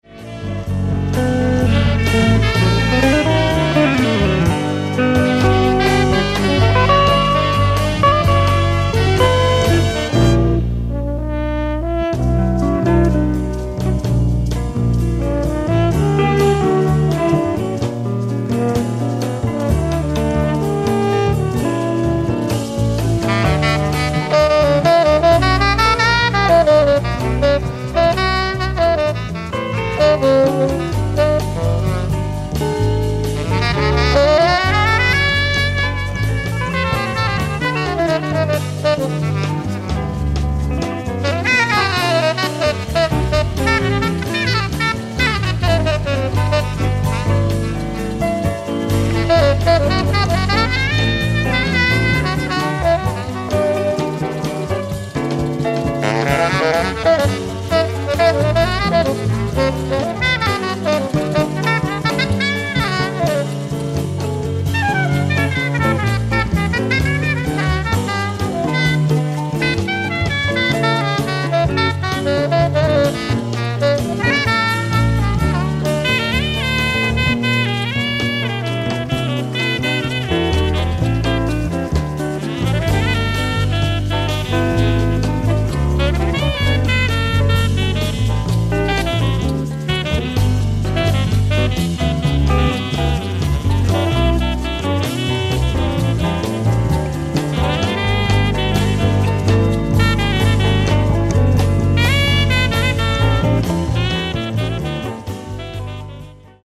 ライブ・アット・ルモーリ・メディテラネーオ、ロセラ・ジョニカ、イタリア 08/27/1987